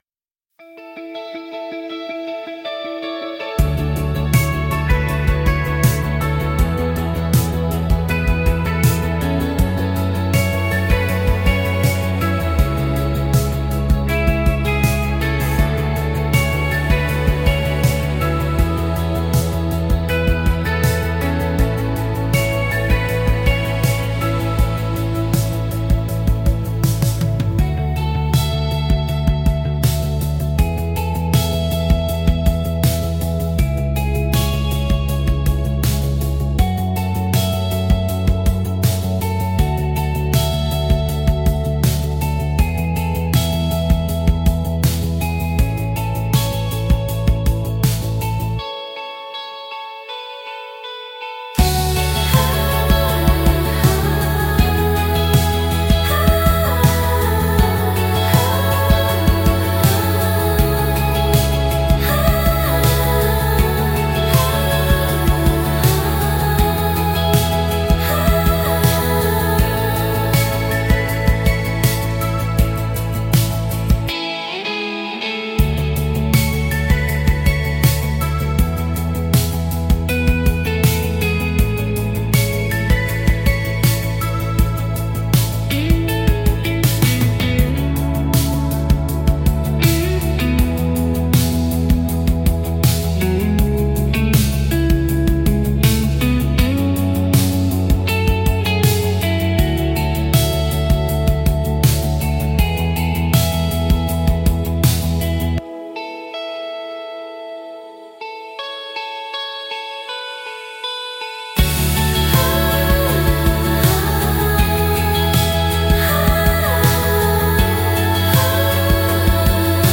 BGMセミオーダーシステムドリームポップは、繊細で幻想的なサウンドが特徴のジャンルです。
静かで美しい音の重なりが心地よく、感性を刺激しながらも邪魔にならない背景音楽として活用されます。